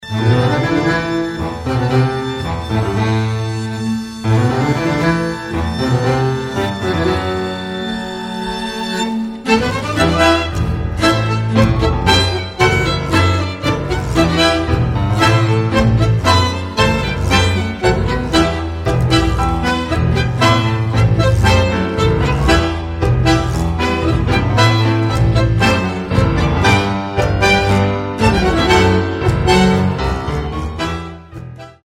Moderne Tangos/ Tango-Atmosphäre/ Non-Tango